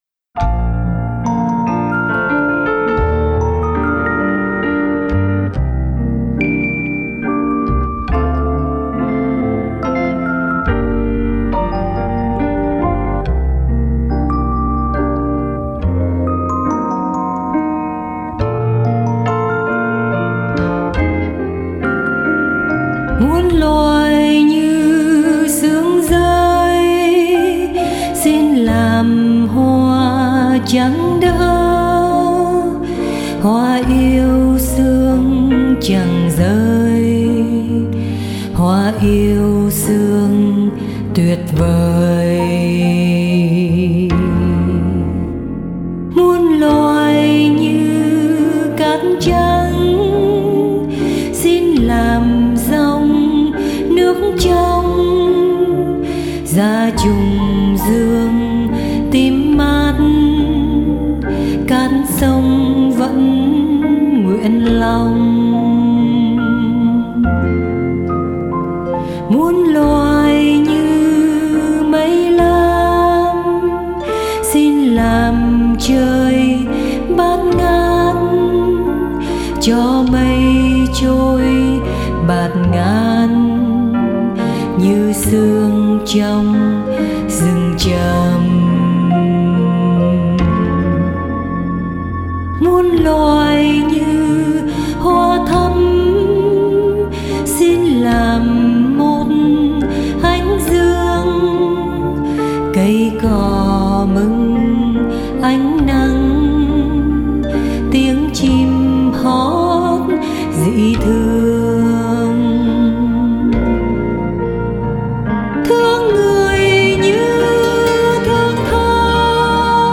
nhẹ nhàng đơn sơ, mộc mạc.